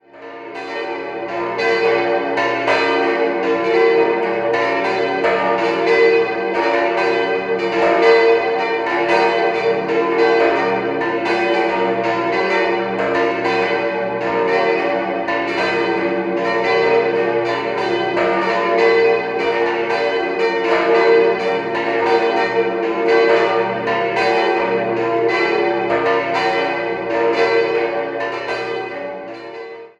In den Jahren 1836/37 erhielt der Turm seine aktuelle Form. 5-stimmiges Geläut: b°-d'-f'-g'-b' Die zweitkleinste Glocke wurde 1964 von Rüetschi in Aarau gegossen, alle anderen entstanden 1837 bei Rosenlächer in Konstanz.